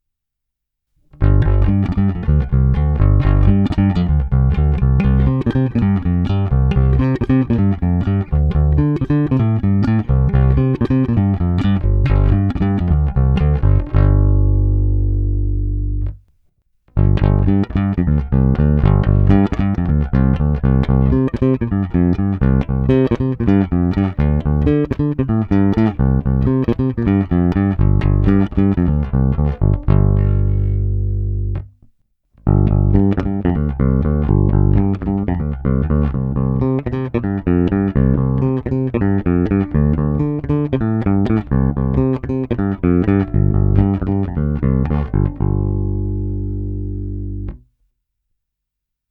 Zvuk je klasický pozdně padesátkový s bohatým spektrem výšek, agresívní, zvonivý s hutným basovým základem.
S oběma typy strun jsem provedl nahrávky rovnou do zvukové karty (není-li uvedeno jinak) a dále ponechal bez úprav, až na normalizaci samozřejmě.
Ve stejném pořadí jako výše – Roundwound